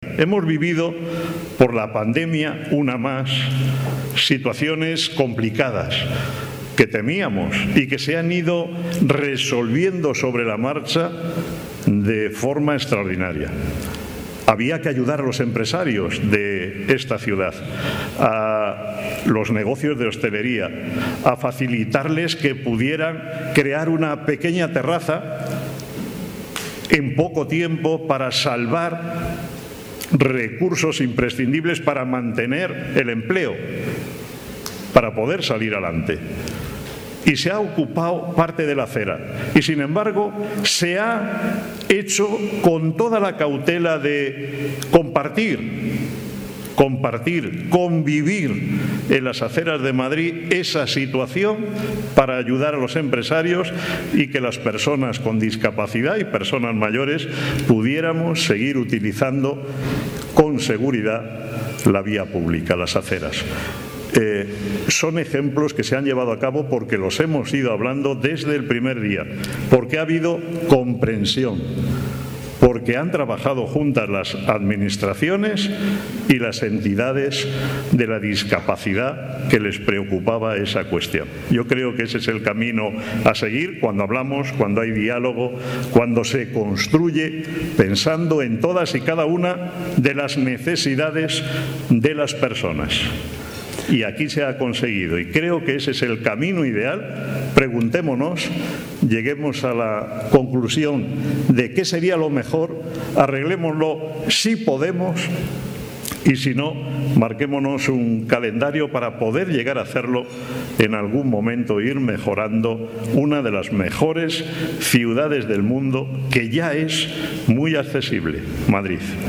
en el transcurso de un desayuno informativo organizado por Nueva Economía Forum